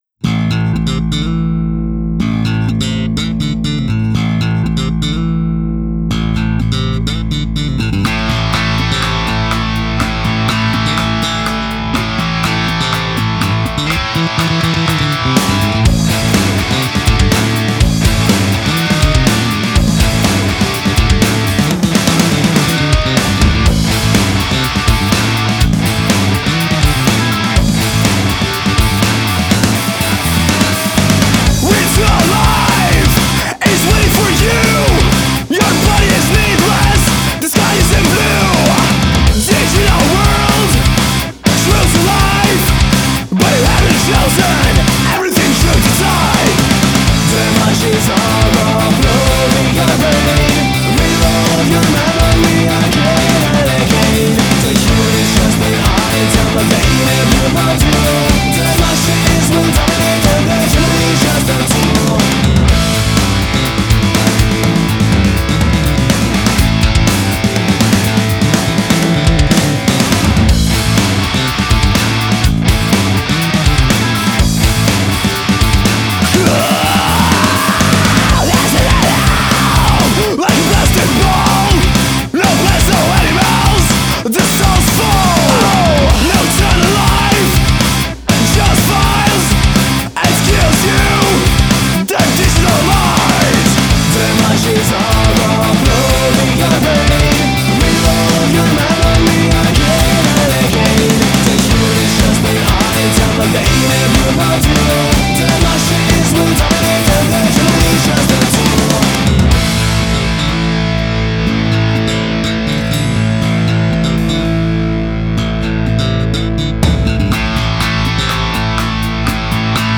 bass/vocals
vocals/guitars